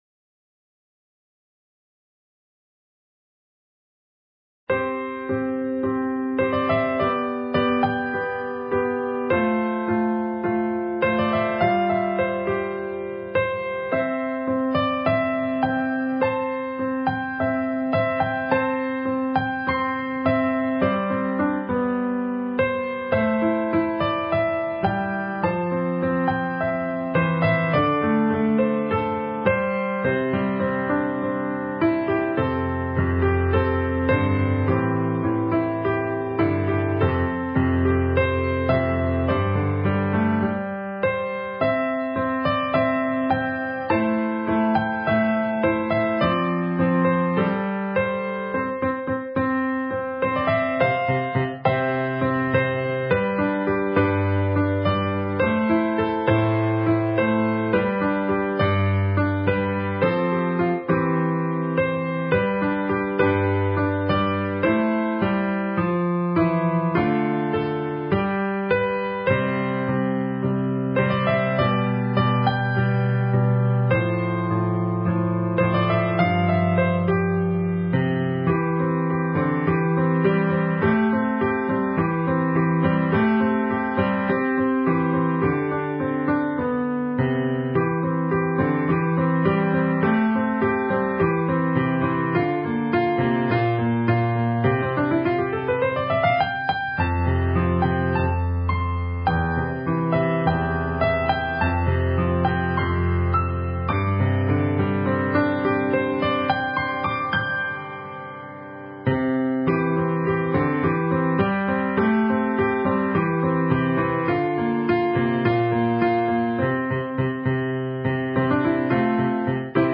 Instrumentation: Piano Solo, optional Violin obbligato
Piano Solo